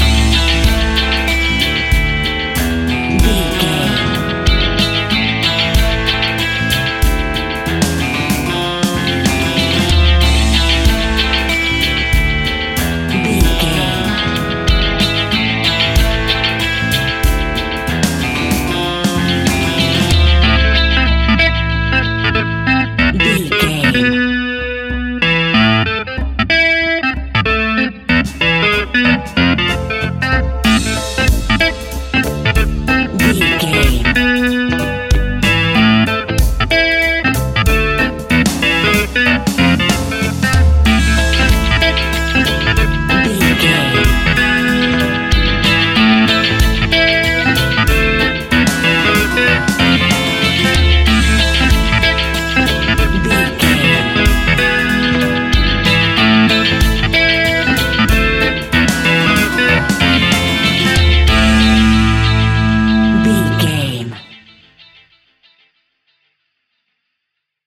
Aeolian/Minor
dub
laid back
chilled
off beat
drums
skank guitar
hammond organ
transistor guitar
percussion
horns